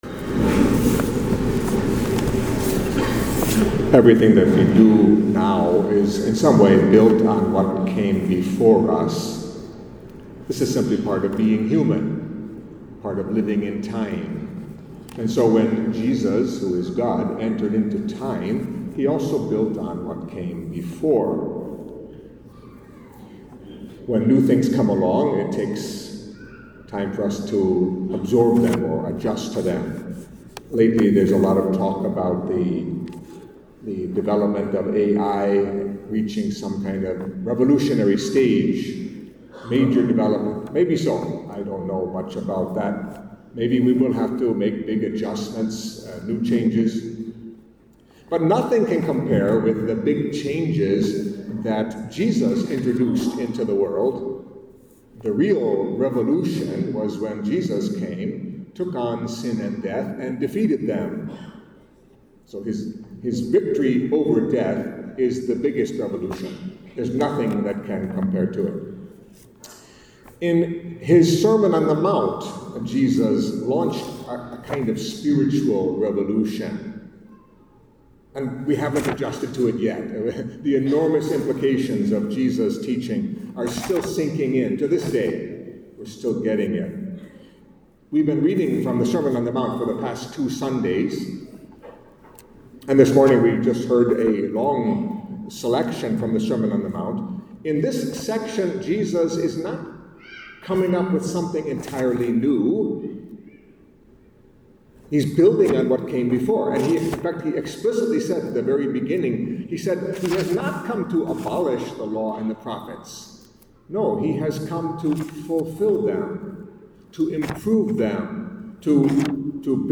Catholic Mass homily for Sixth Sunday in Ordinary Time